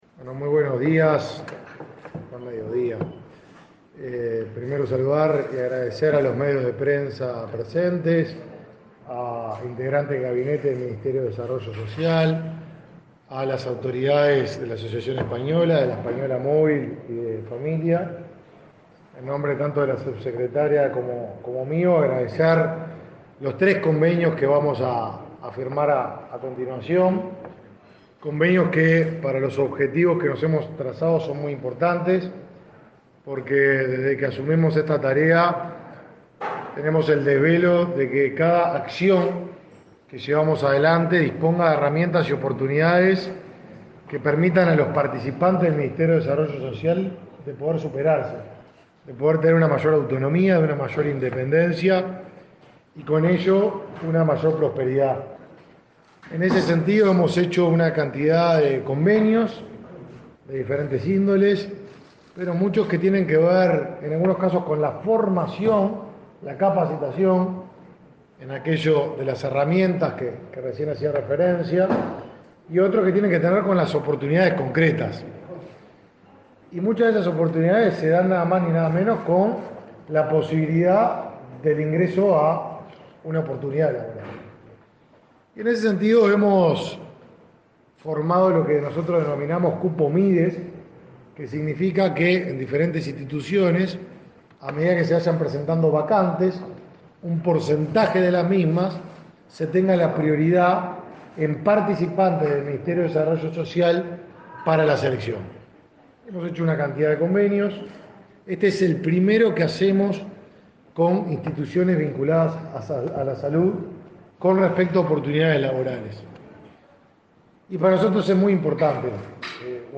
Conferencia de prensa por la firma de convenio laboral entre el Mides y la Asociación Española